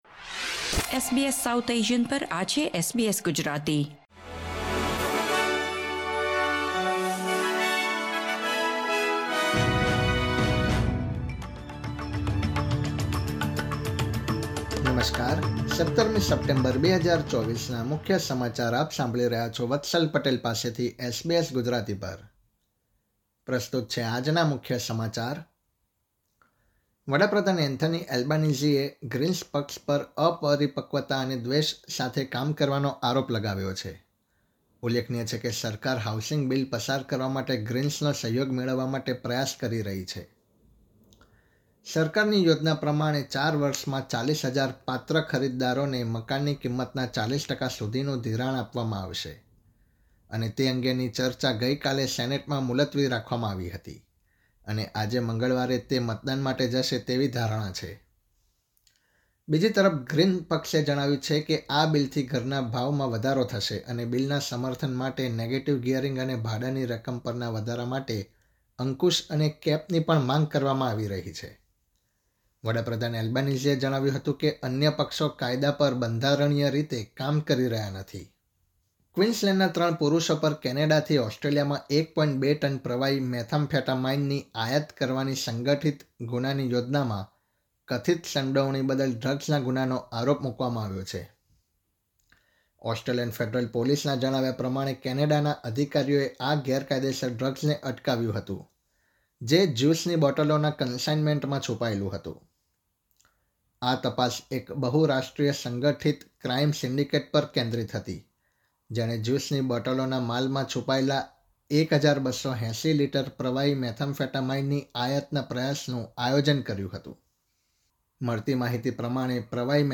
SBS Gujarati News Bulletin 17 September 2024